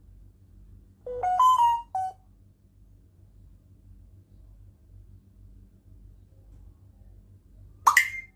Bird mimicks samsung notification sounds
bird-mimicks-samsung-notification-sounds.mp3